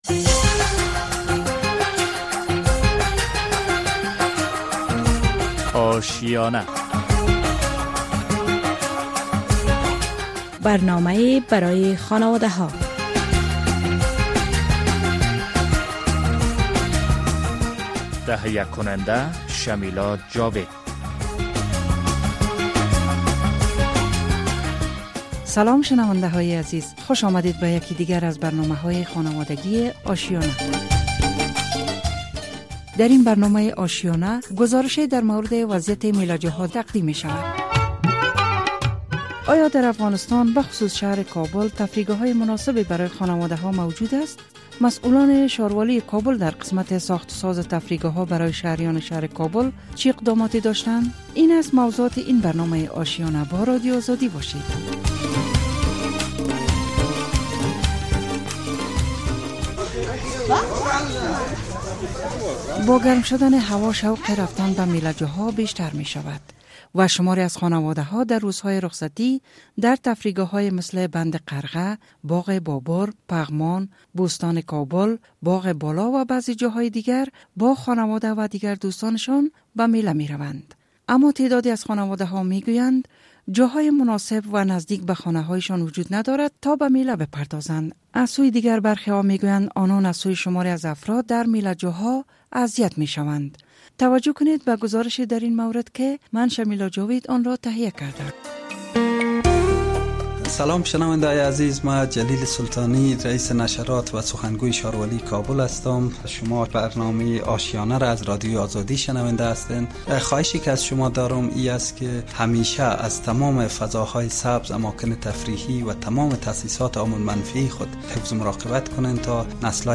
خوش آمدید به یکی دیگر از برنامه‌های خانواده‌گی آشیانه. در این برنامه گزارشی در مورد وضعیت میله جاها برای ...